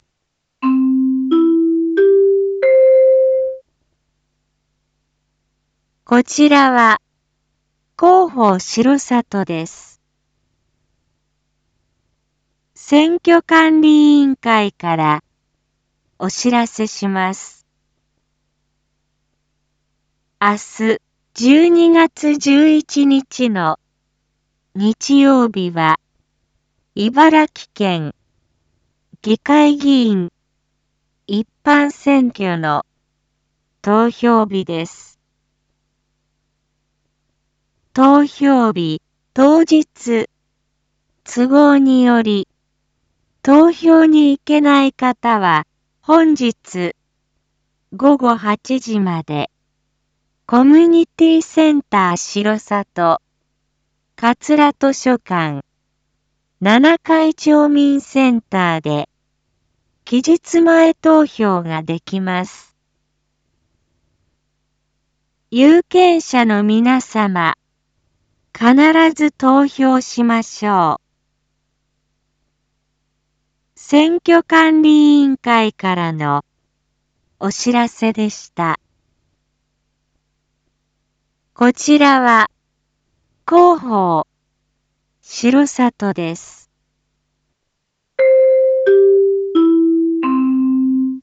一般放送情報
Back Home 一般放送情報 音声放送 再生 一般放送情報 登録日時：2022-12-10 19:01:33 タイトル：茨城県議会議員一般選挙について（期日前投票前日） インフォメーション：こちらは、広報しろさとです。